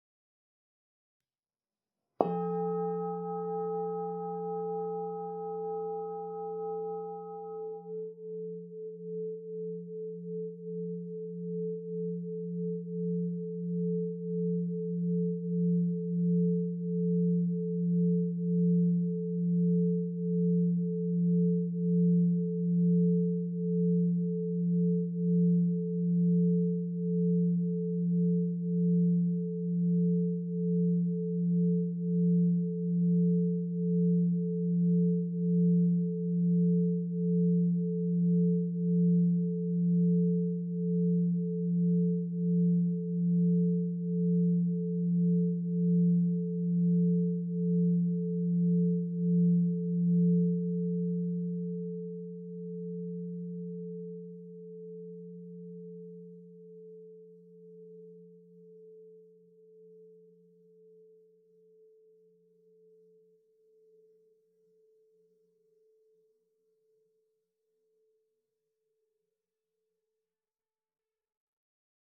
Meinl Sonic Energy 8" Essence Solfeggio Crystal Singing Bowl 174 Hz, Brandy Rose (ESOLCSB174)